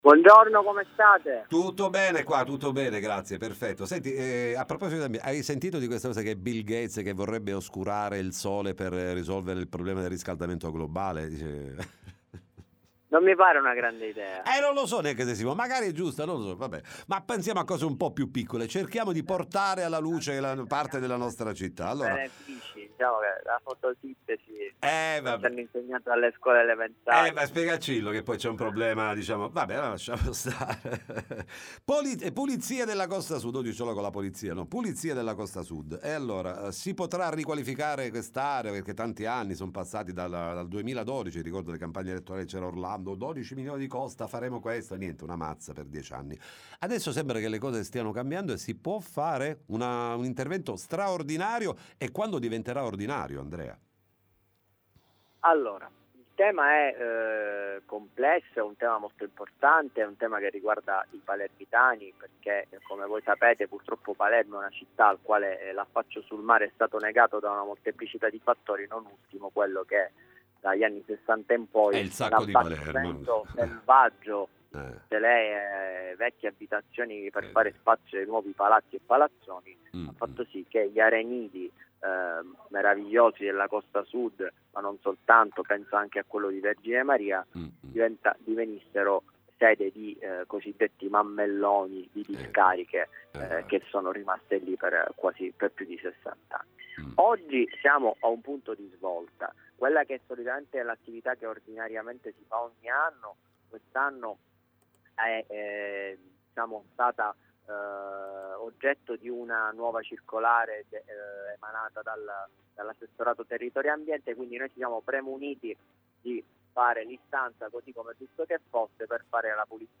Pulizia della Costa Sud a Palermo, ne parliamo con Andrea Mineo, Ass. Com. all’ambiente
Pulizia della Costa Sud a Palermo Interviste Time Magazine 06/07/2023 12:00:00 AM / Time Magazine Condividi: Pulizia della Costa Sud a Palermo, ne parliamo con Andrea Mineo, Ass.